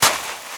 STEPS Sand, Walk 22.wav